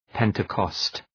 {‘pentıkɒst}